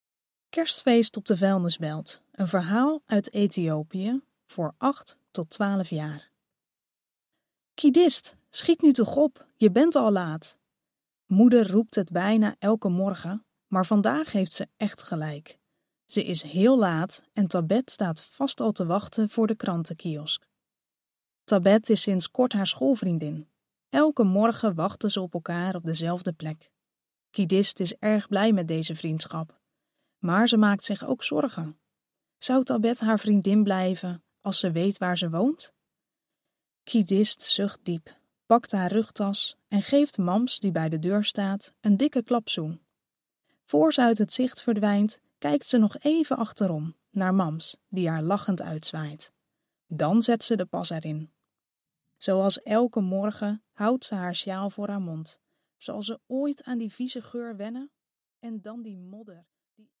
De verhalen zijn ook als luisterboek verkrijgbaar.